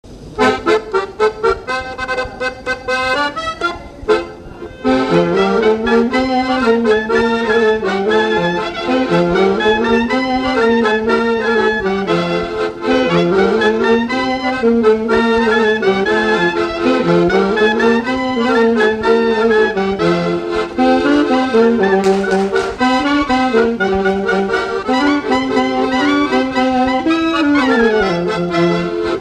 instrumental
danse : polka des bébés ou badoise
Pièce musicale inédite